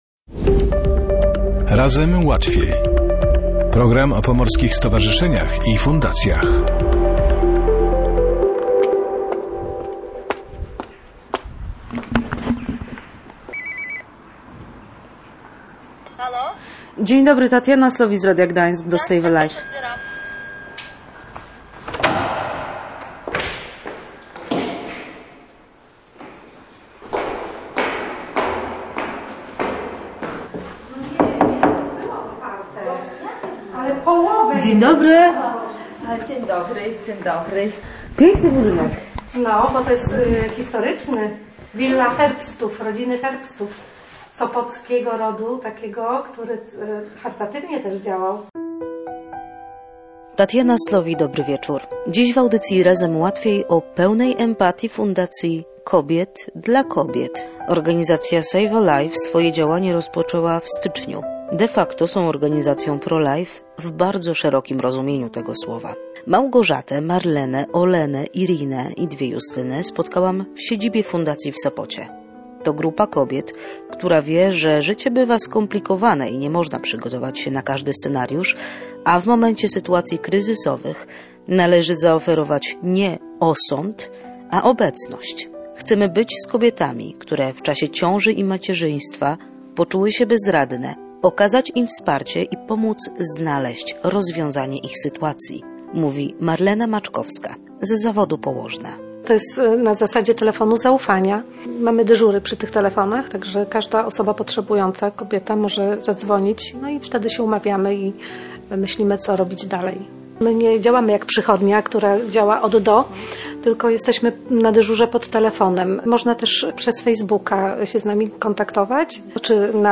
spotkałam w siedzibie Fundacji w Sopocie.